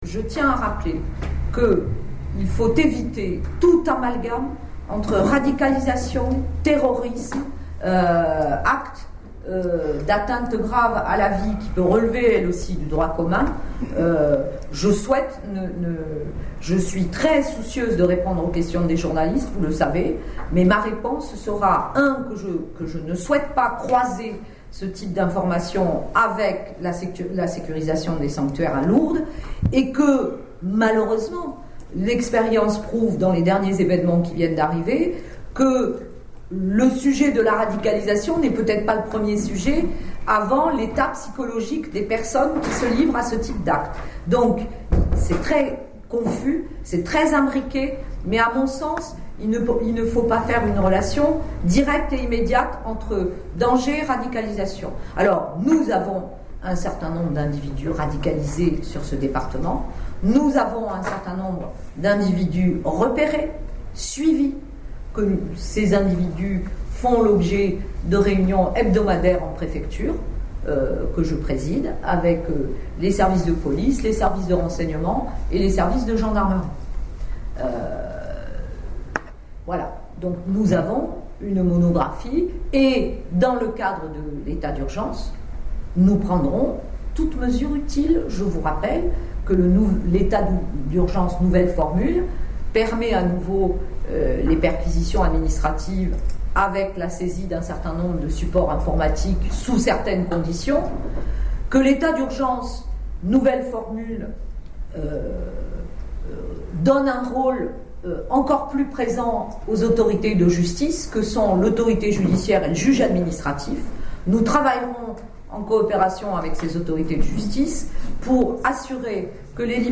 préfète des Hautes-Pyrénées a tenu une conférence de presse en mairie de Lourdes
La préfète : « Eviter tout amalgame »